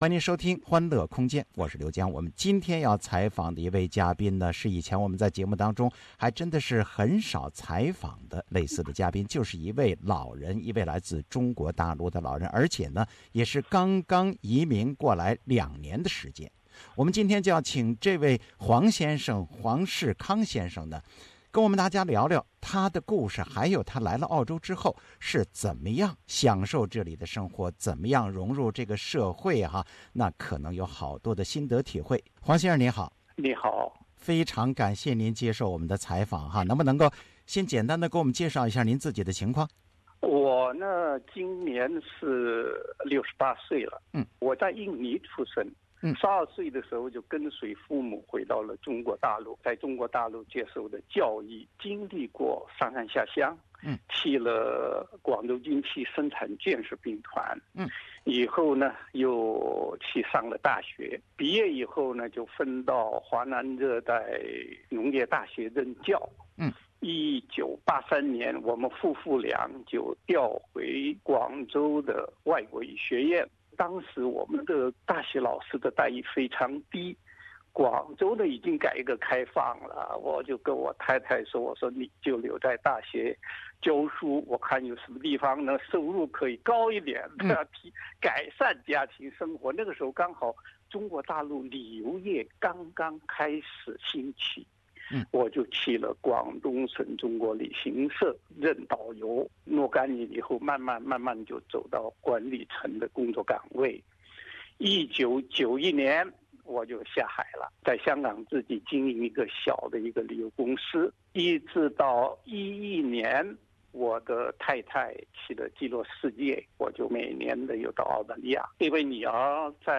欢迎点击音频收听对他的采访